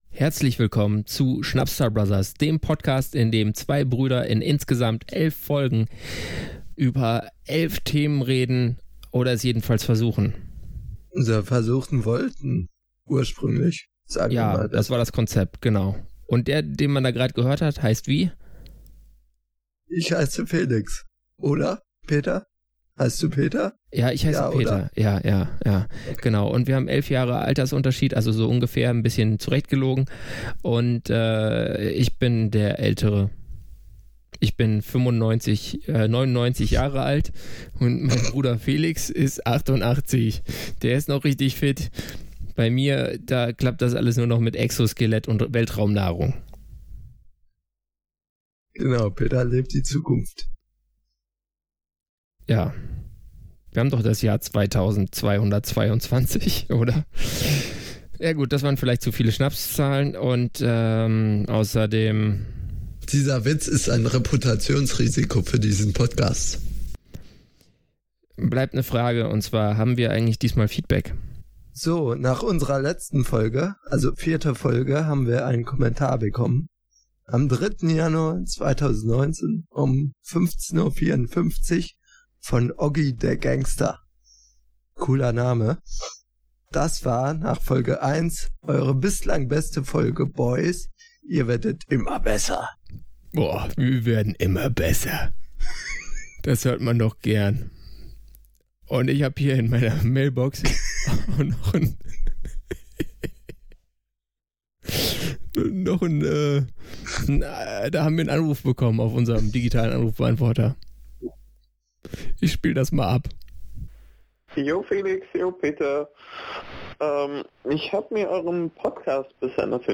Das neue Jahr beginnt mit Audio-Problemen bei beiden Protagonisten